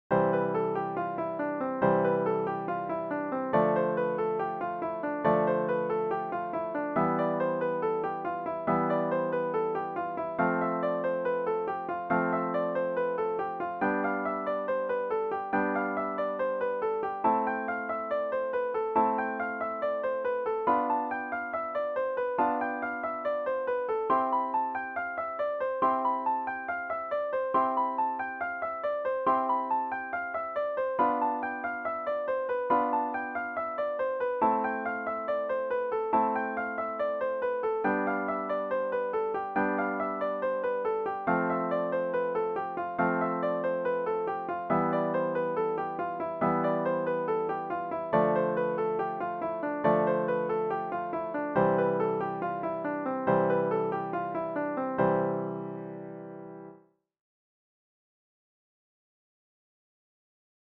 🎹 Piano Technique Exercise